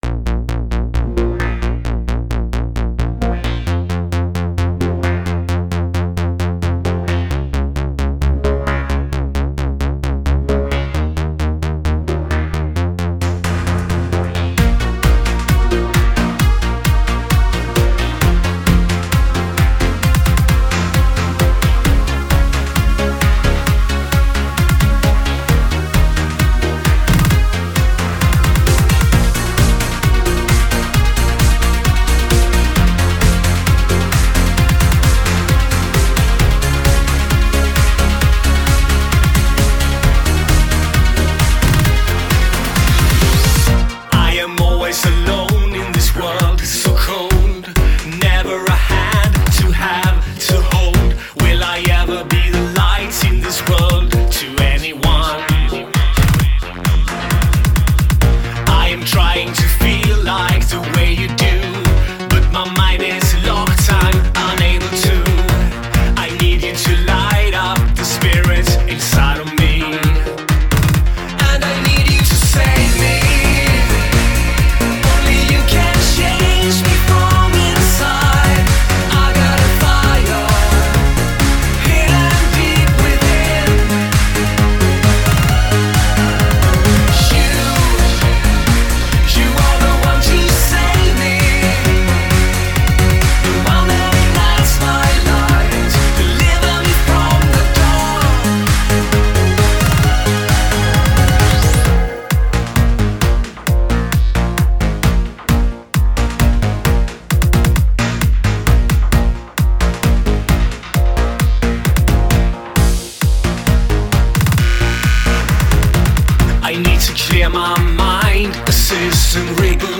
RSAudio RSA 02/18/2019 Welcome to the third and penultimate week of the 2019 membership drive! This week I seem to have hit a vein of sorta house-ish synthpop and EBM or at the very least my selections remind me of a genre of music I was heavily into in the mid 90s.